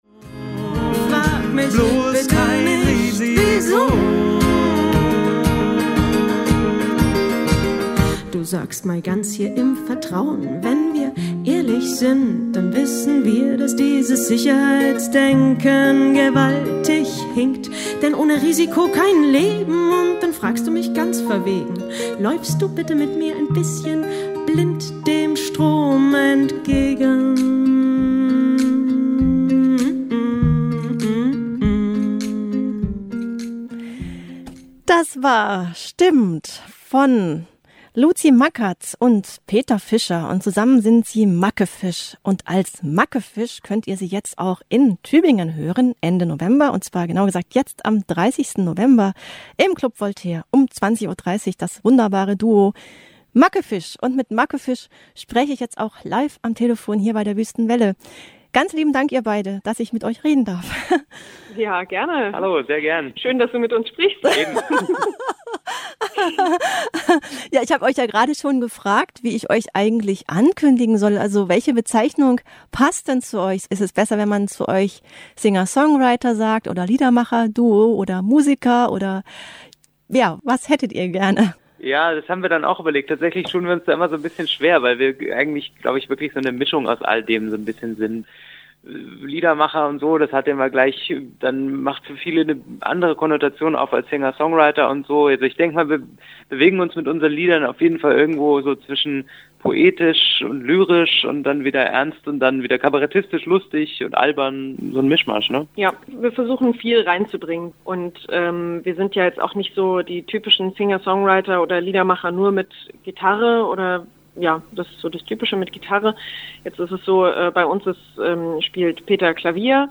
Im Interview erzählen sie vom Zauber des gemeinsamen Musikmachens und davon, worauf sie sich bei ihren Konzerten am meisten freuen.